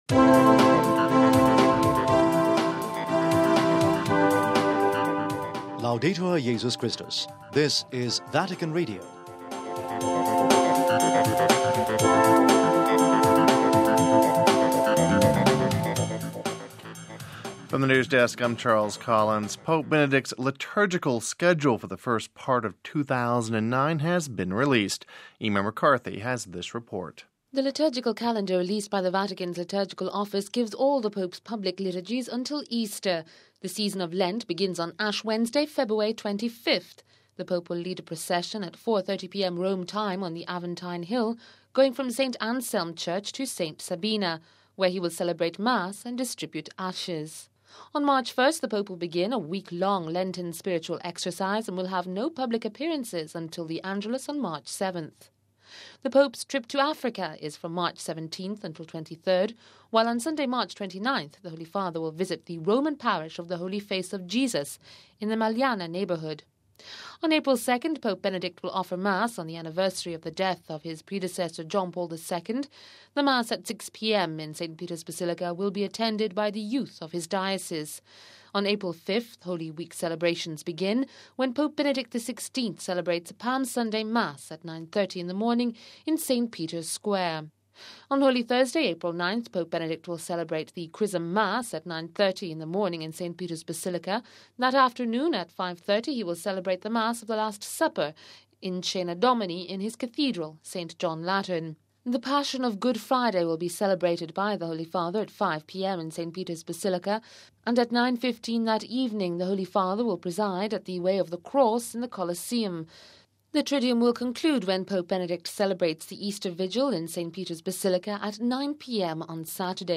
Home Archivio 2009-02-05 16:24:09 Pope Benedict XVI's Schedule for Lent and Easter Released (05 Feb 09 - RV) Pope Benedict’s liturgical schedule for the first part of 2009 has been released. We have this report...